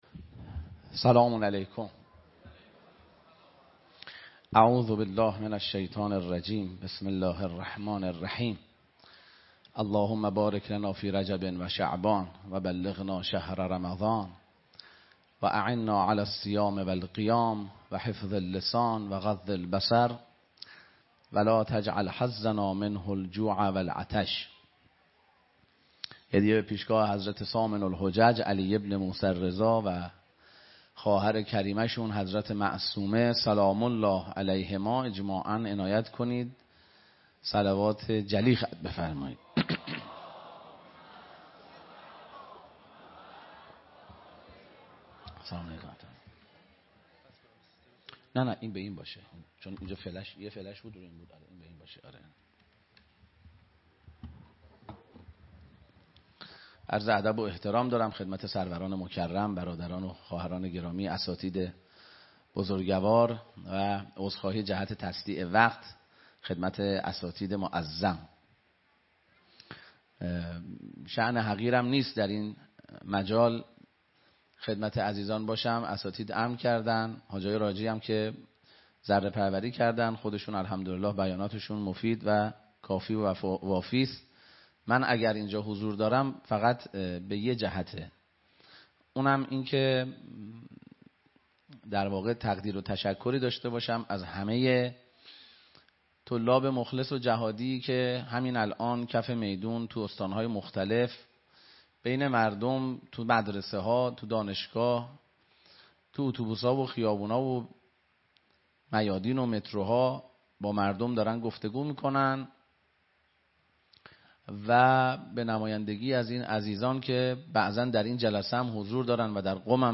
سخنرانی
در سلسله نشست های طلیعه رمضان 1447ه.ق